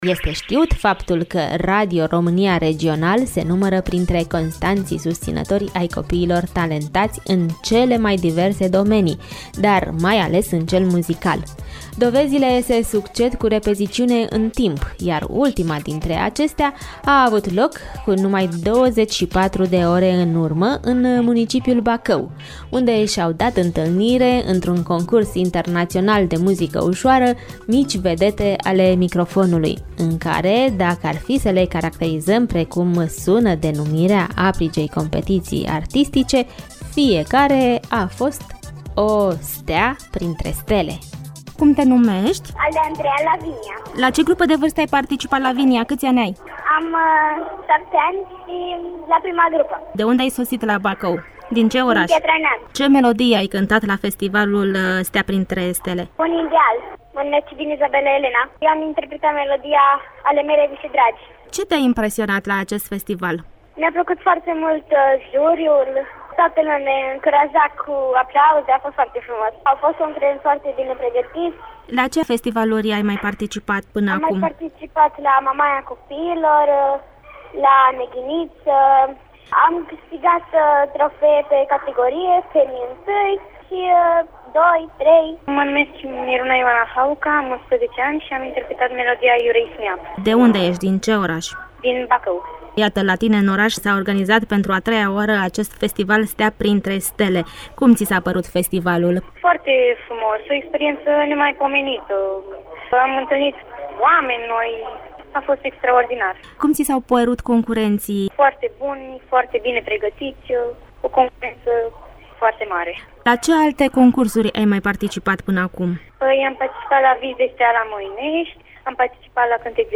Interviu despre Festivalul STEA PRINTRE STELE – Bacău 2014